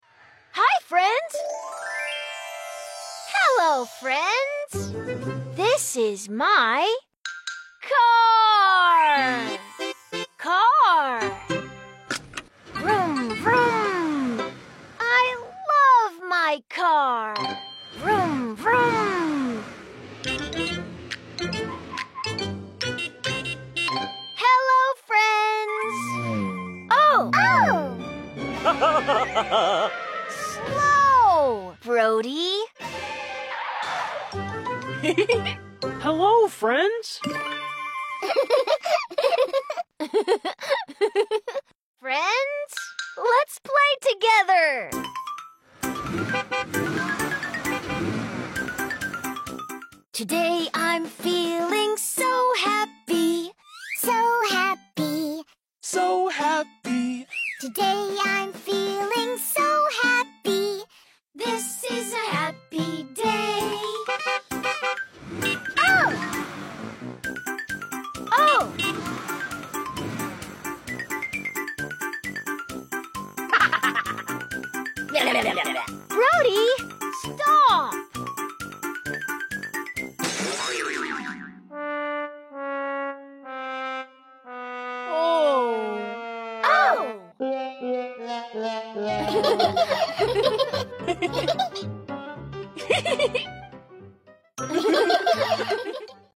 Join the ride full of giggles, sounds & excitement!